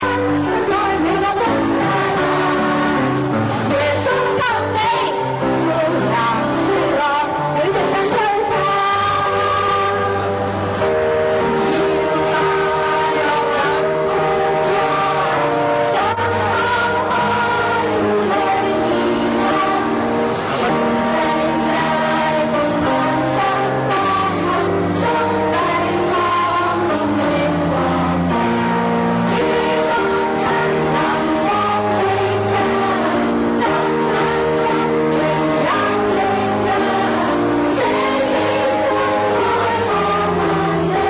Athena singing carols on Christmas:[Listen to it]
carols.ra